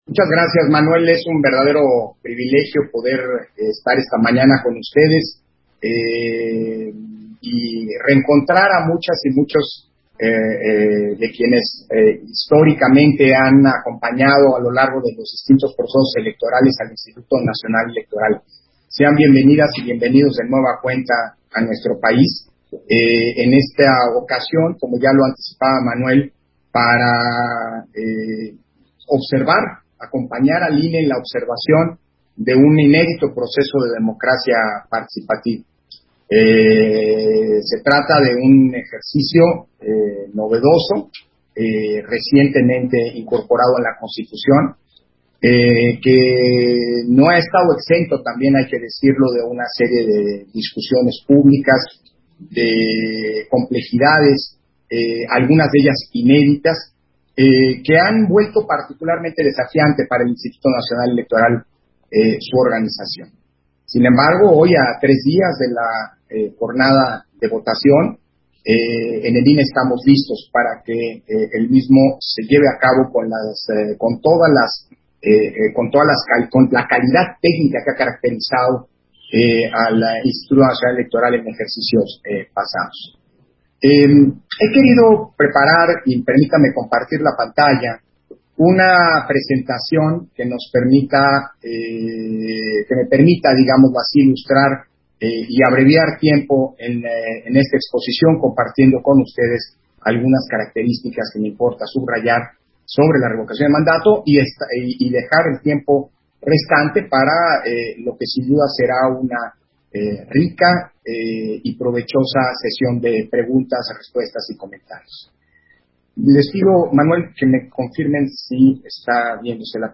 070422_AUDIO_INTERVENCIÓN-CONEJERO-PDTE.-CÓRDOVA-FORO-INFORMATIVO-PARA-VISITANTES-EXTRANJEROS - Central Electoral